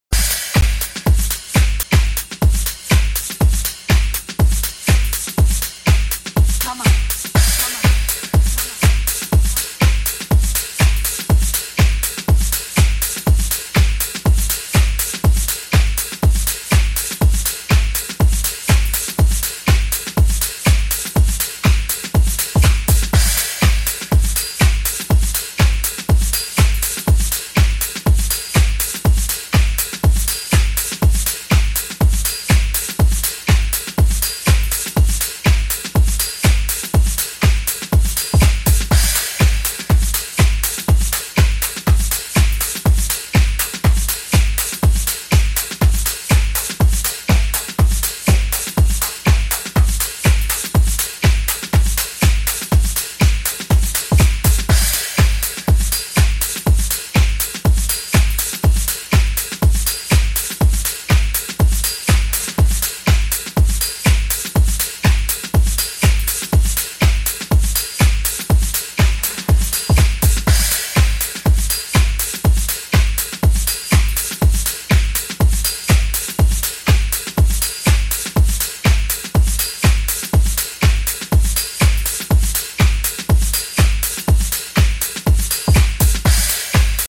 supplier of essential dance music
House